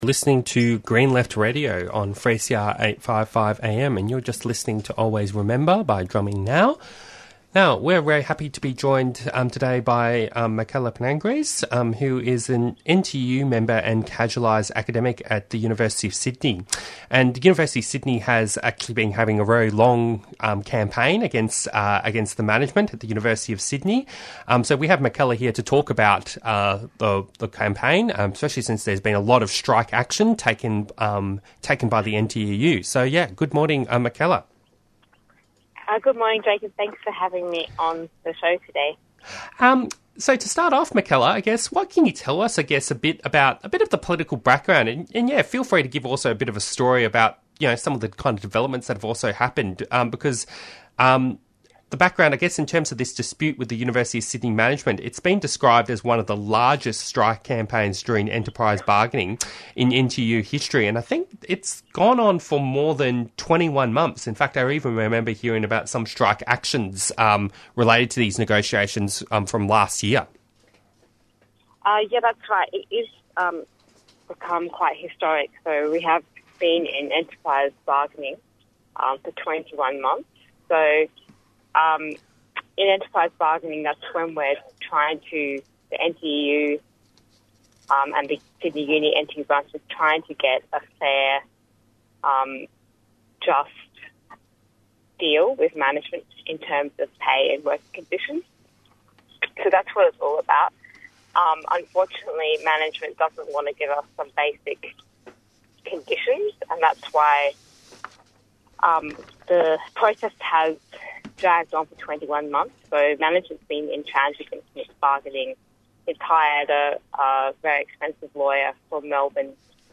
Interviews and Discussion